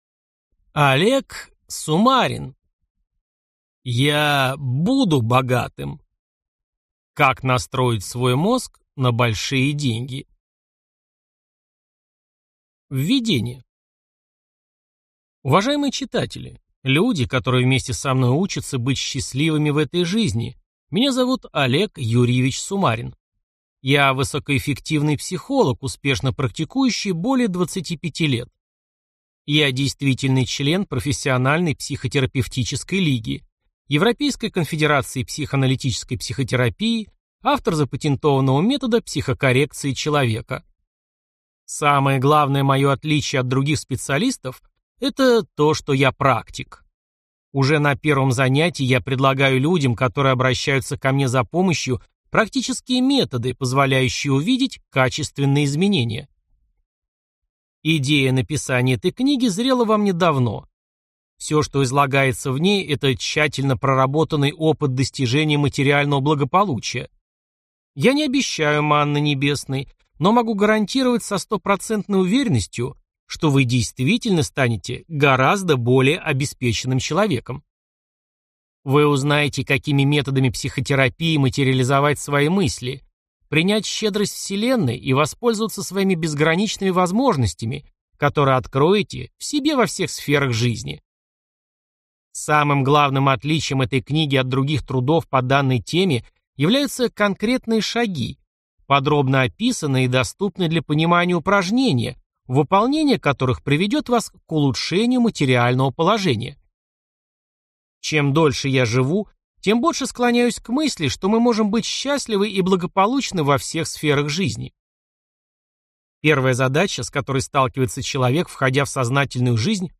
Аудиокнига Я буду богатым! Как настроить свой мозг на большие деньги | Библиотека аудиокниг